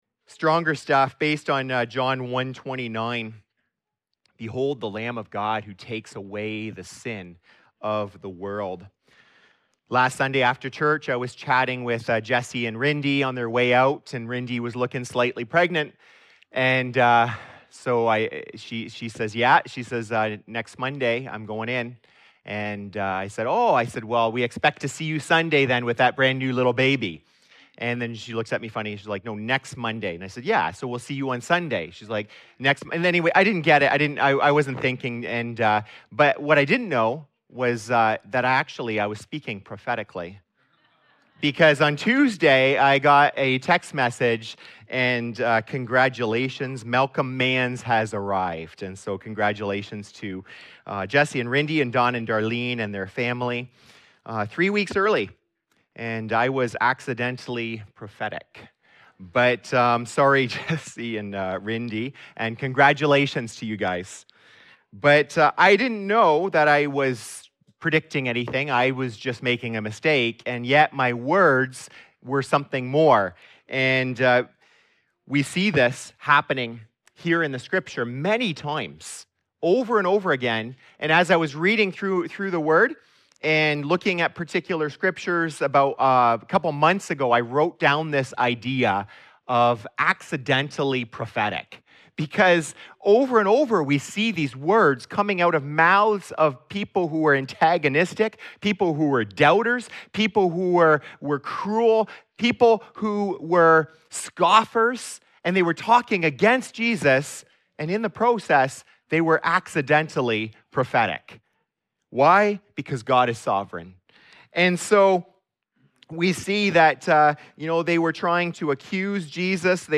This sermon reminds us that nothing can derail God's plan—not people, not sin, not even our mess.